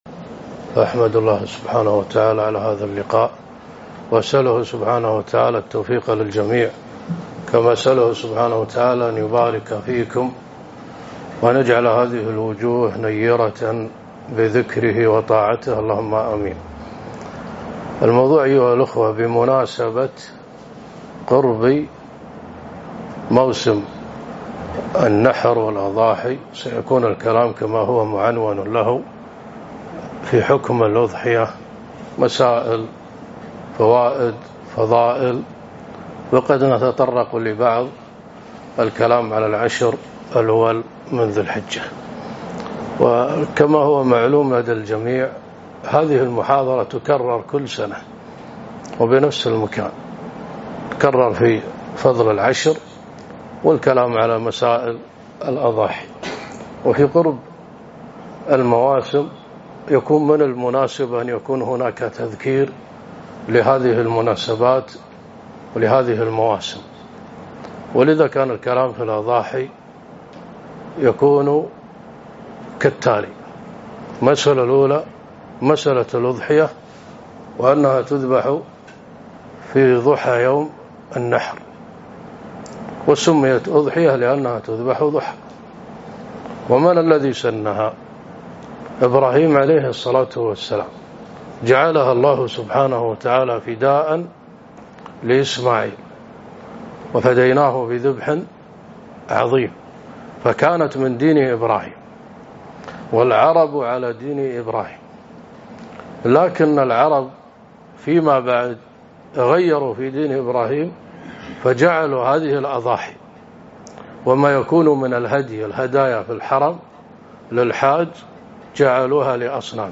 محاضرة - الأضحية فضلها وأحكامها ومسائل متعلقة بها